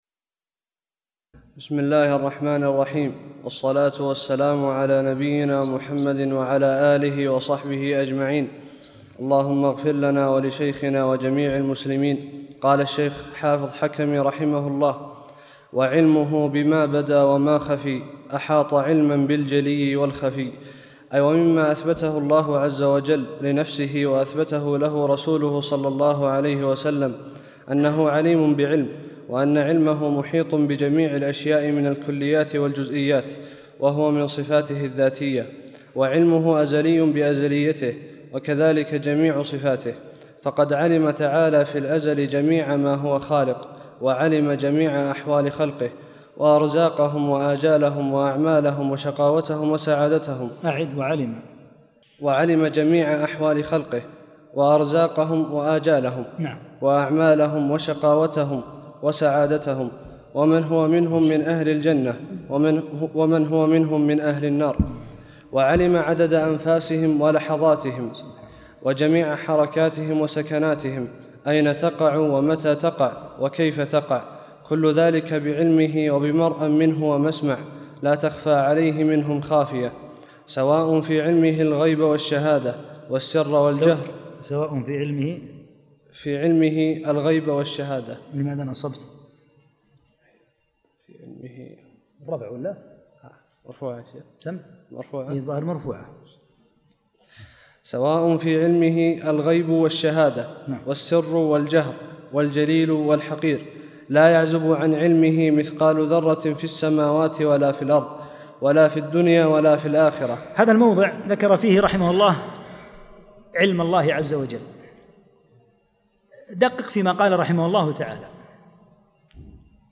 32- الدرس الثاني والثلاثون